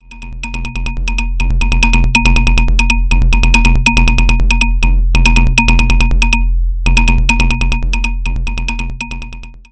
kategorien : Electronica
tags : trance psy psytrance progressive